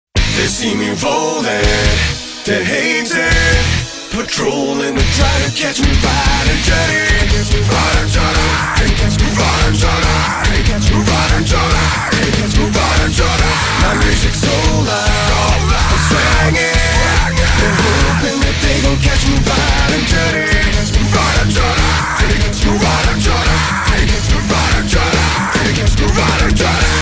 • Качество: 192, Stereo
мужской голос
громкие
жесткие
электрогитара
скриминг
зарубежный рок
Крутой рок кавер